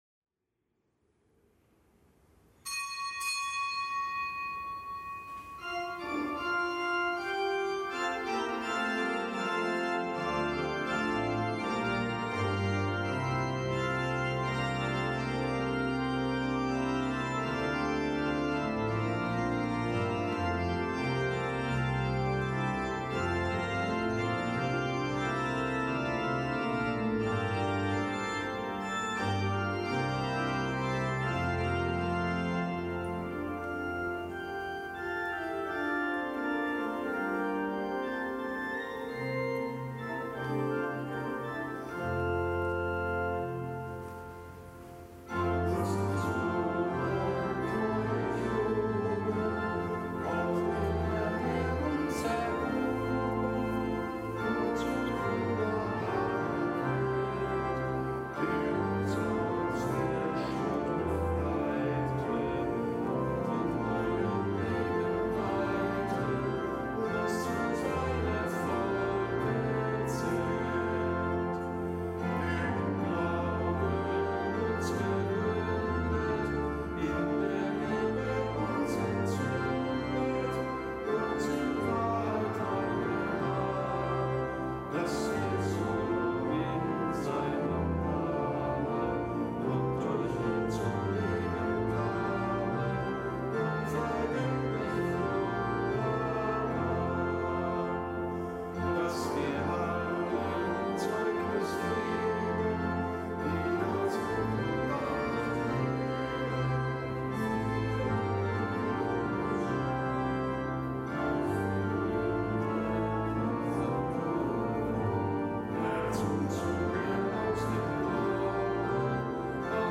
Kapitelsmesse aus dem Kölner Dom am Mittwoch der einundzwanzigsten Woche im Jahreskreis.